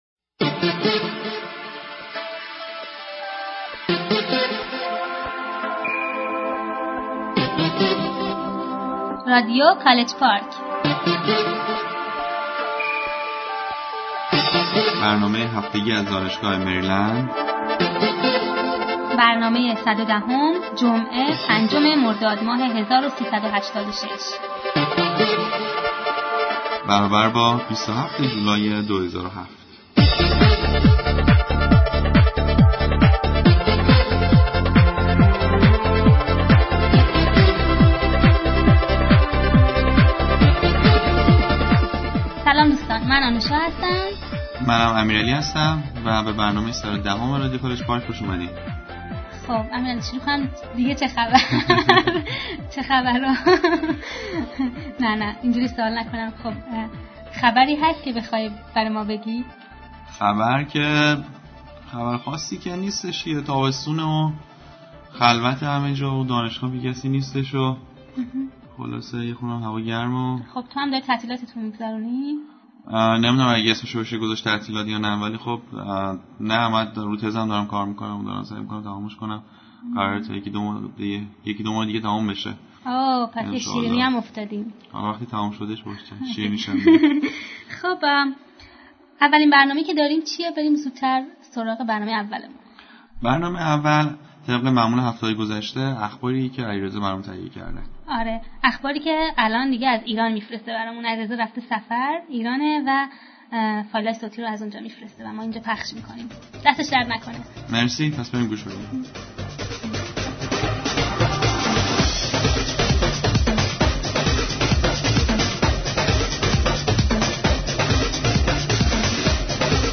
Weekly News
Interview with Fatemeh Motamedarya